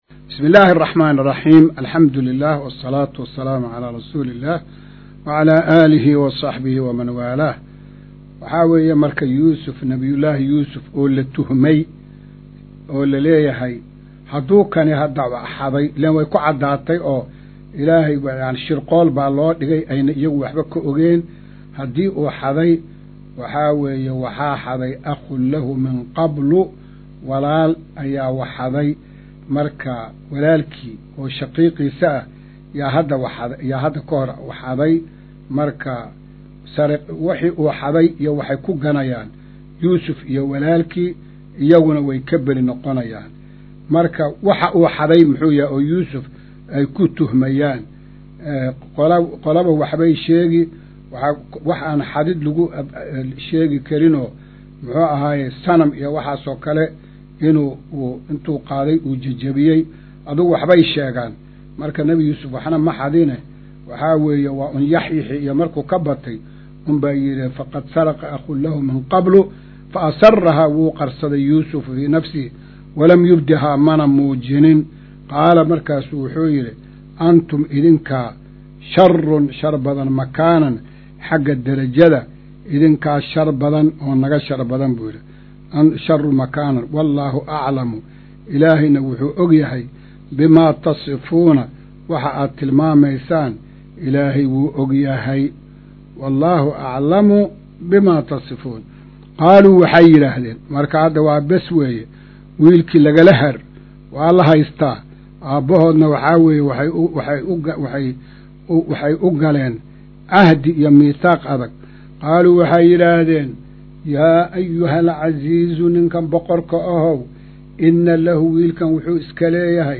Maqal:- Casharka Tafsiirka Qur’aanka Idaacadda Himilo “Darsiga 121aad”